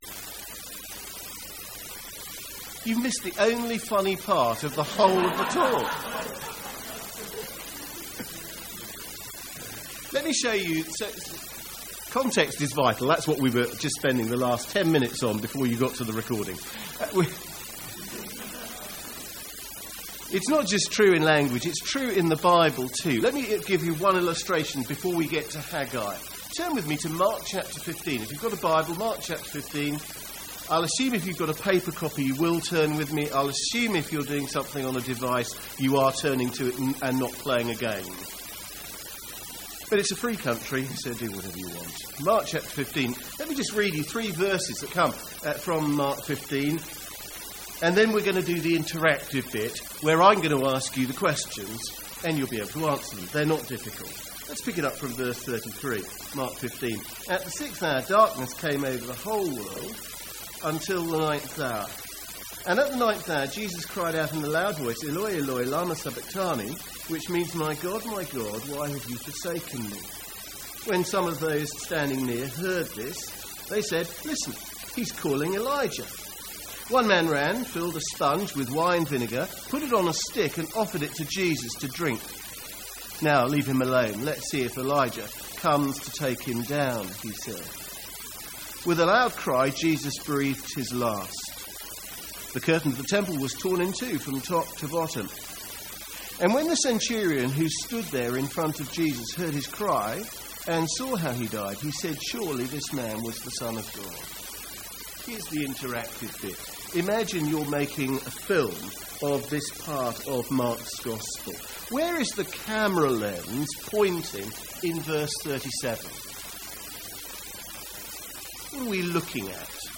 The first talk on Haggai from MYC 2017.